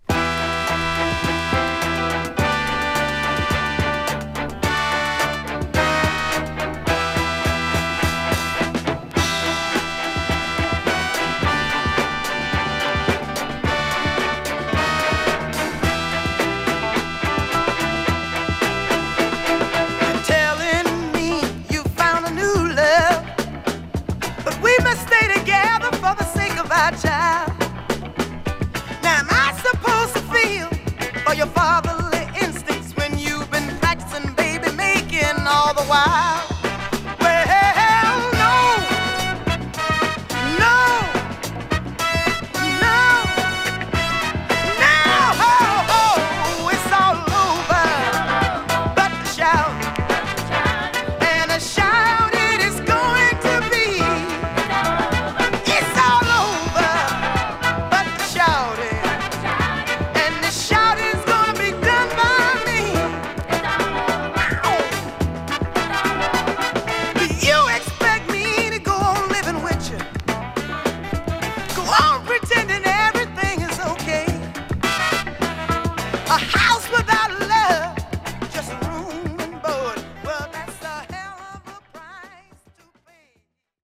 2. > SOUL/FUNK
女性ソウル〜R&Bシンガー